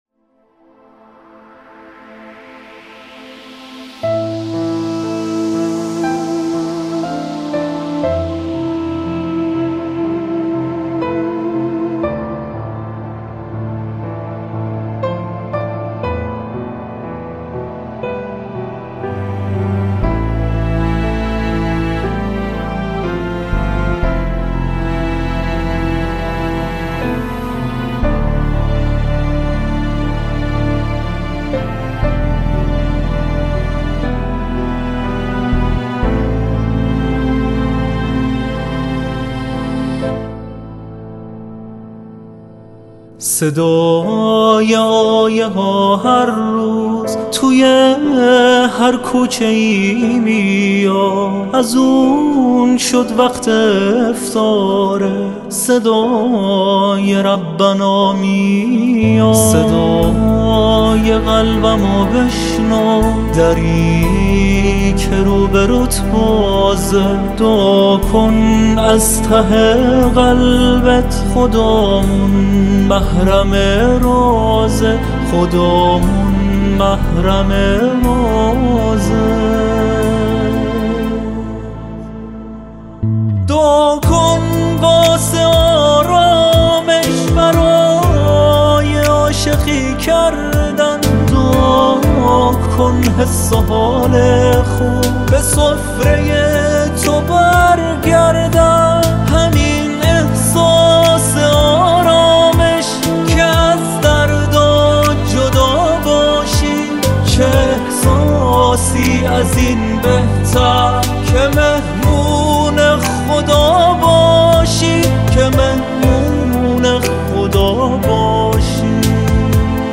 خواننده نواهای دینی و مذهبی